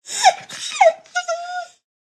Minecraft Version Minecraft Version 1.21.5 Latest Release | Latest Snapshot 1.21.5 / assets / minecraft / sounds / mob / wolf / sad / whine.ogg Compare With Compare With Latest Release | Latest Snapshot
whine.ogg